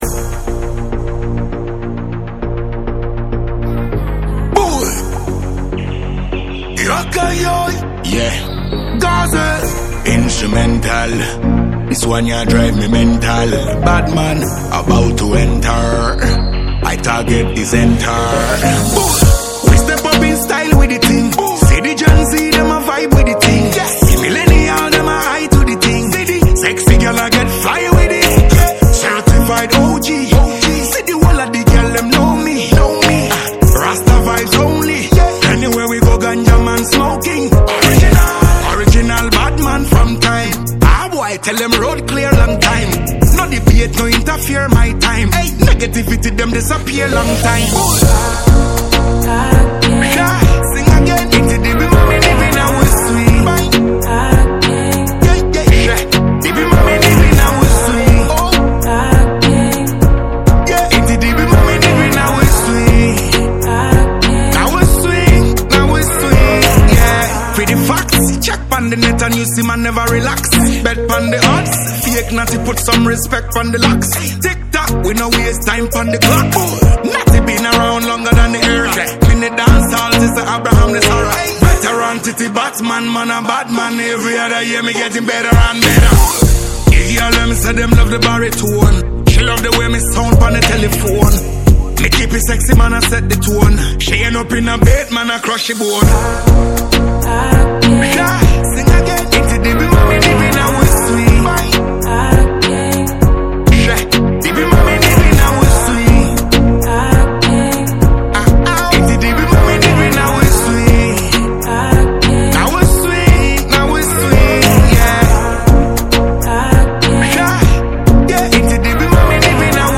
• Genre: Reggae / Afro-Dancehall